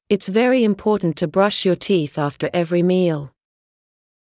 ｲｯﾂ ﾍﾞﾘｰ ｲﾝﾎﾟｰﾀﾝﾄ ﾄｩ ﾌﾞﾗｯｼｭ ﾕｱ ﾃｨｰｽ ｱﾌﾀｰ ｴﾌﾞﾘｰ ﾐｰﾙ